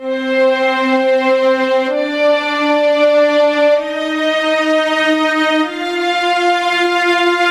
夜幕降临 弦乐2 128 bpm
Tag: 128 bpm Electronic Loops Strings Loops 1.26 MB wav Key : C